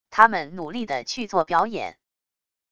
他们努力地去做表演wav音频生成系统WAV Audio Player